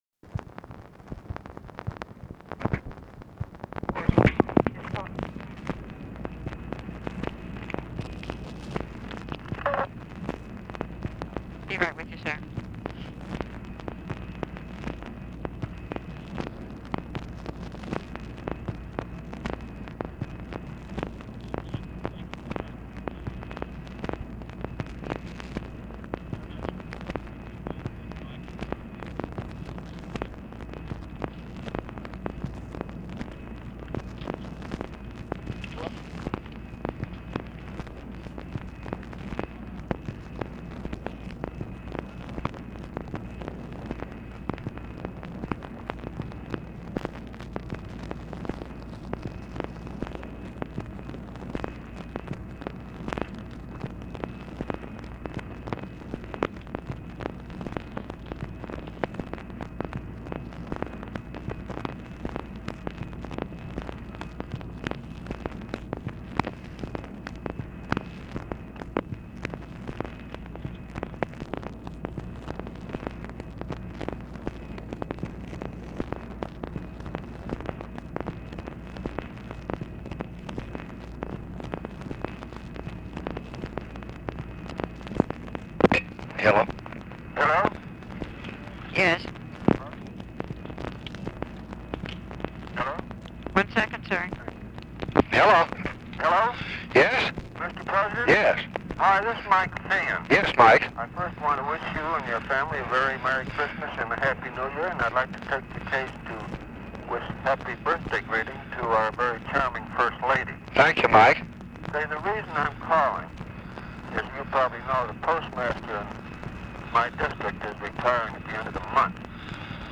Conversation with MICHAEL FEIGHAN, December 22, 1966
Secret White House Tapes